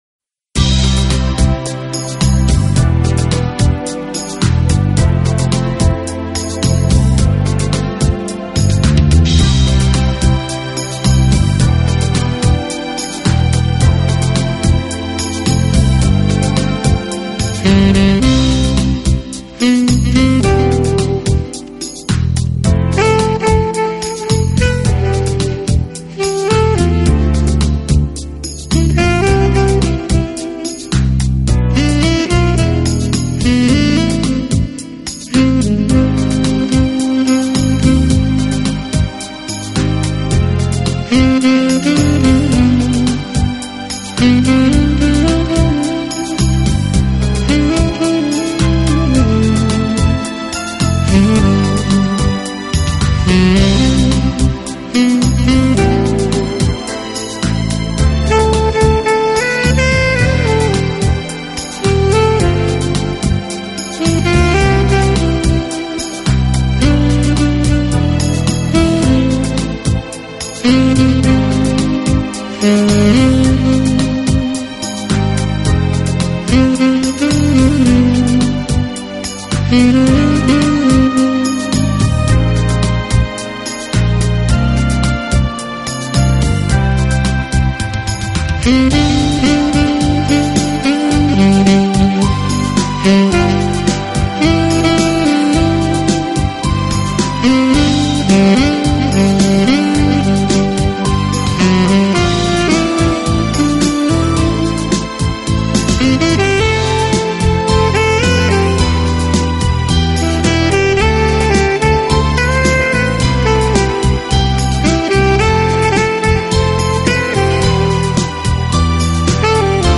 【爵士萨克斯】
用萨克管演奏情调爵士乐，上世纪六十年代开始很走红，到上个世纪七十年